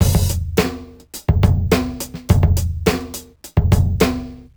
Index of /musicradar/sampled-funk-soul-samples/105bpm/Beats